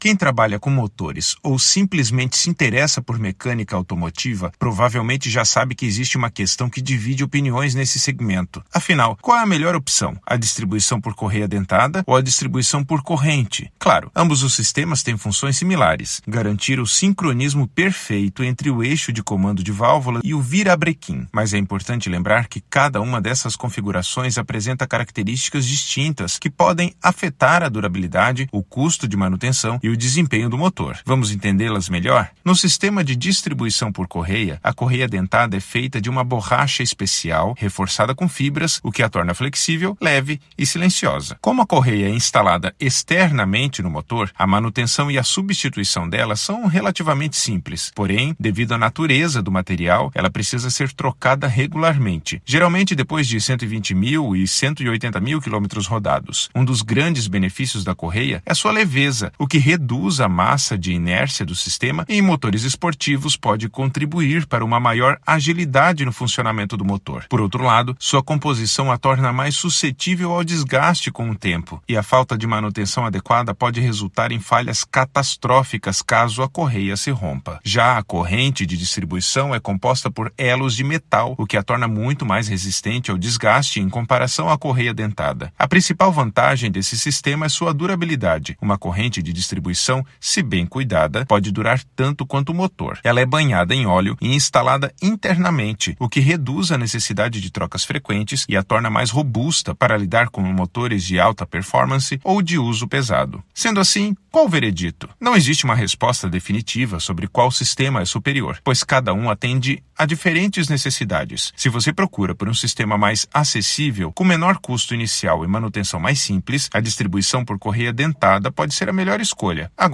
Narracao-02-distribuicao-por-correias.mp3